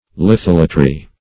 Search Result for " litholatry" : The Collaborative International Dictionary of English v.0.48: Litholatry \Li*thol"a*try\, n. [Litho- + Gr.